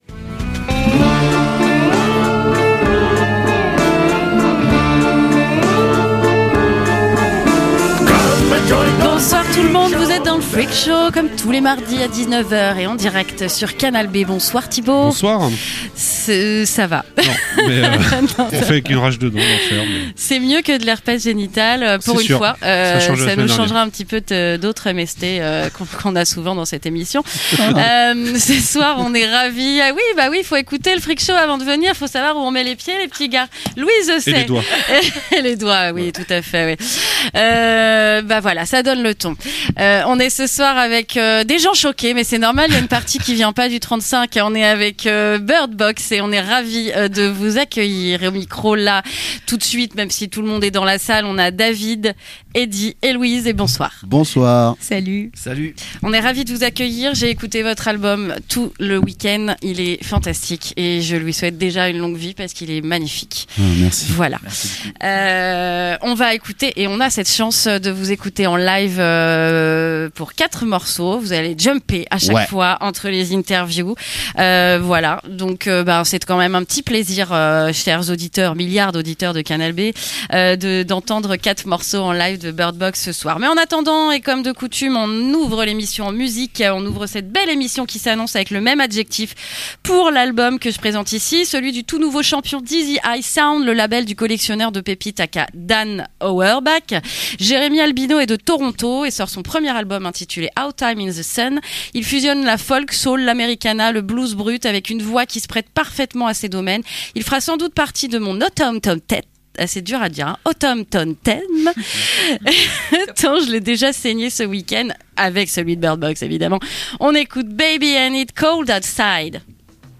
Les excellents Birdbox sont venu en studio nous faire 4 morceaux en live et nous presenter leur album